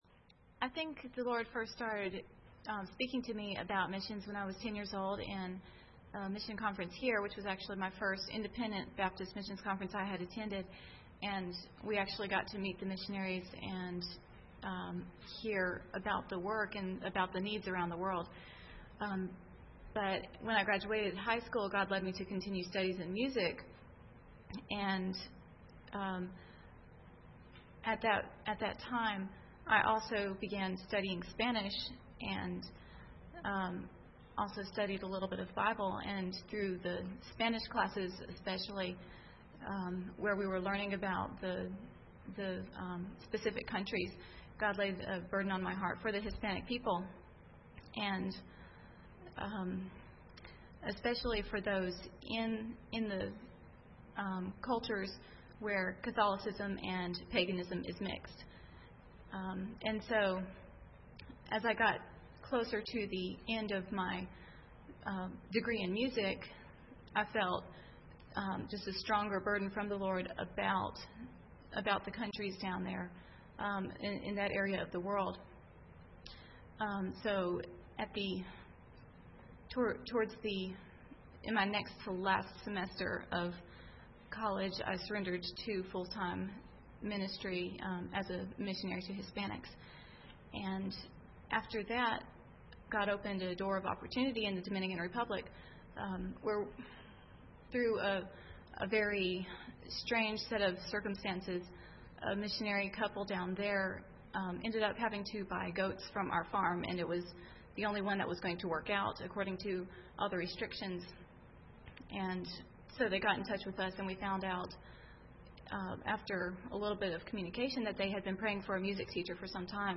Preacher
Service Type: Sunday Evening